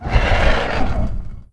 c_horisath_hit1.wav